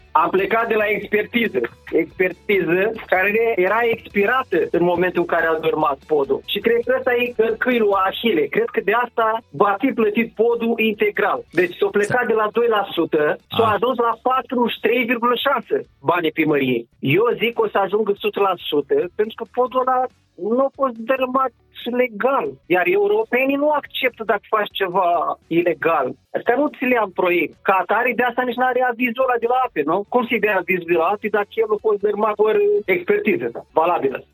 Insert audio – cetățean